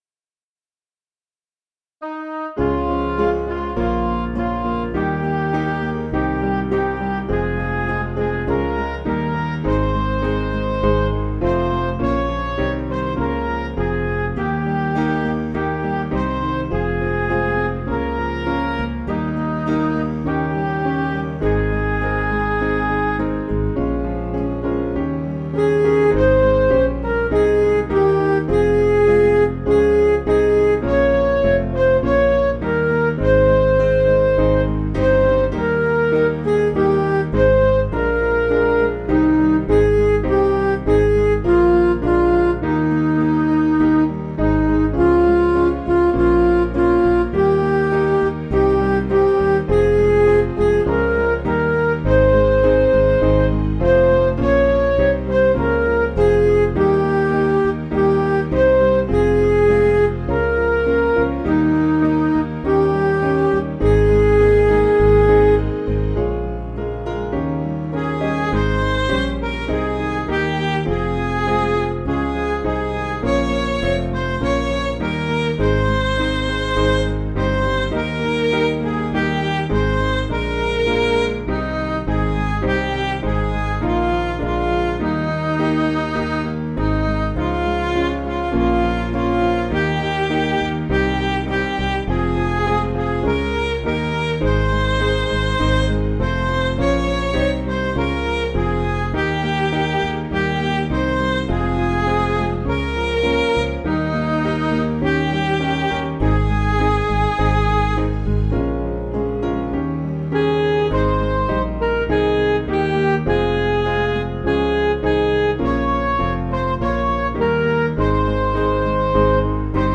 Piano & Instrumental
(CM)   5/Ab